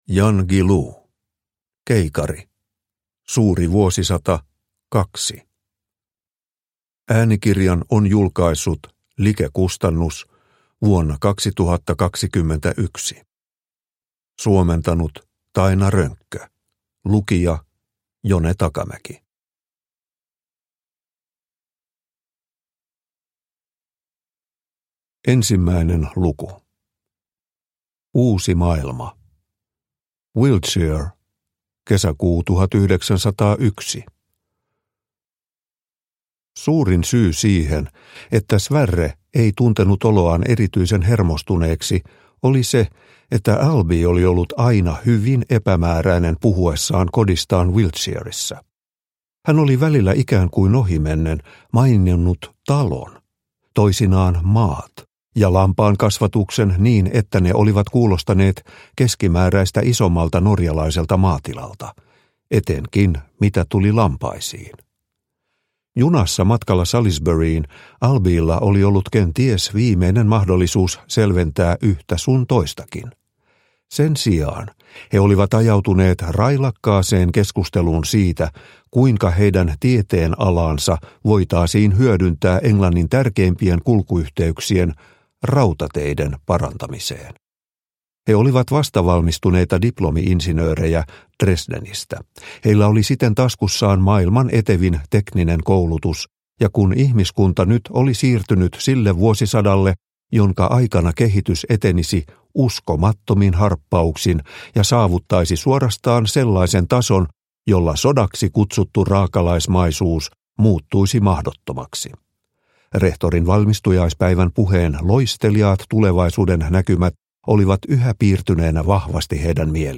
Keikari – Ljudbok – Laddas ner